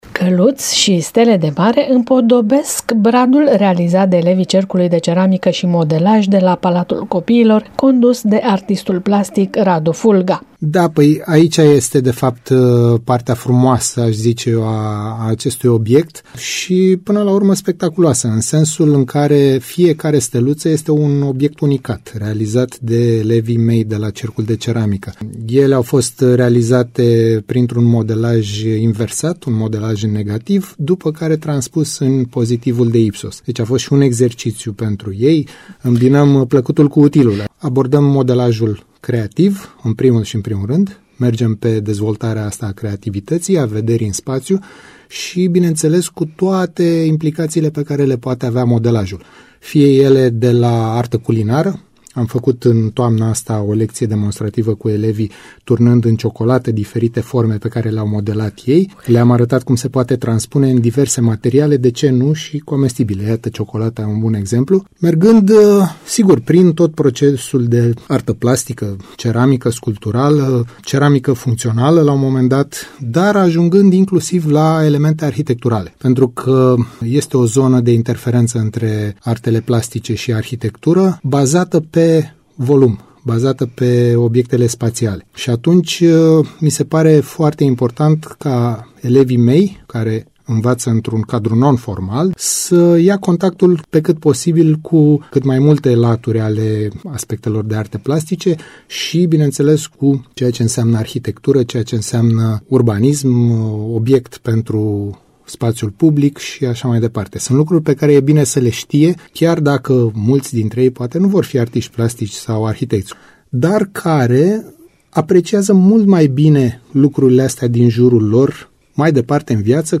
Artistul plastic